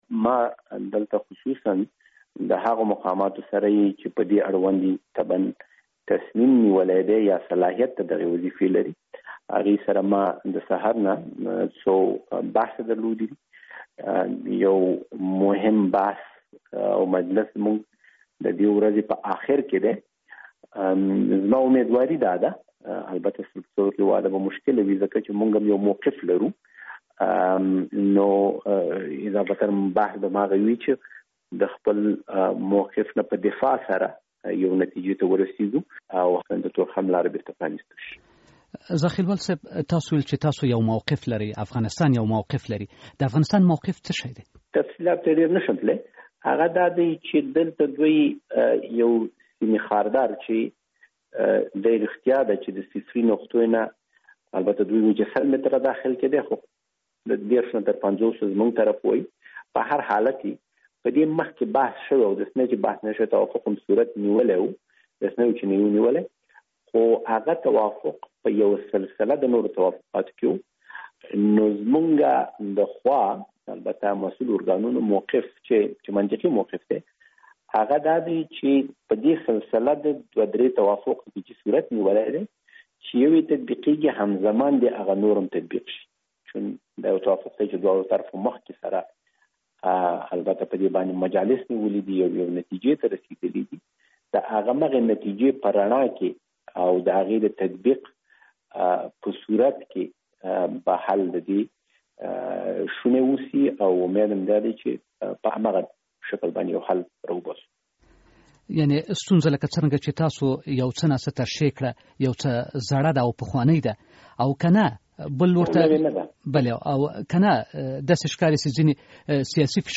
مرکه
له عمر ذاخېلوال سره مرکه